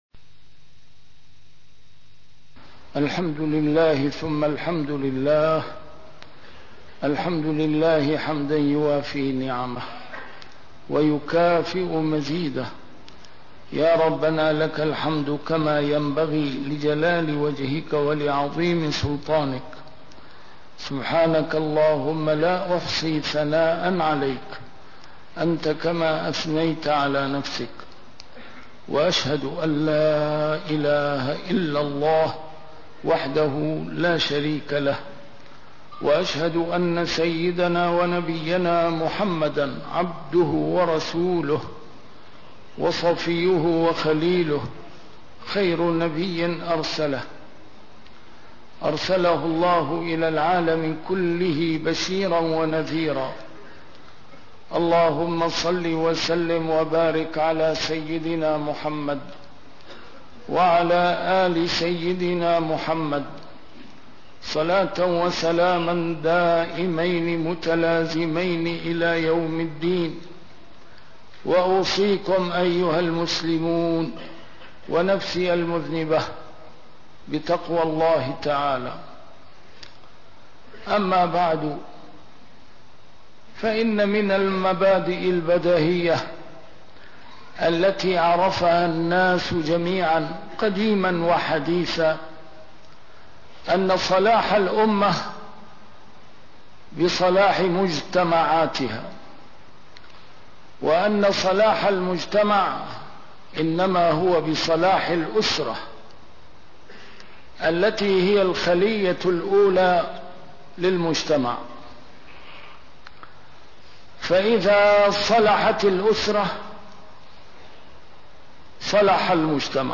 A MARTYR SCHOLAR: IMAM MUHAMMAD SAEED RAMADAN AL-BOUTI - الخطب - حقوق المرأة بين الإسلام والغرب